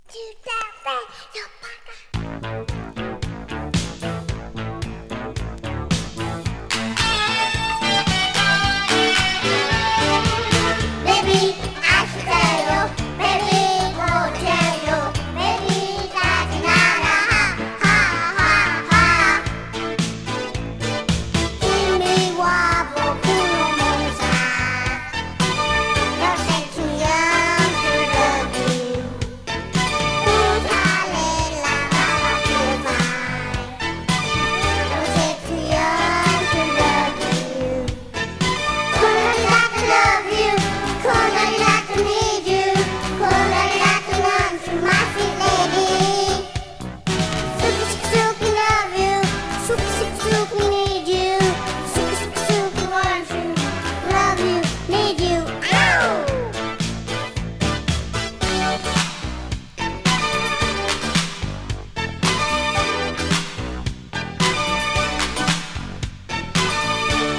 キュートなキッズ・グループによる
虫声キッズ・シンセポップ！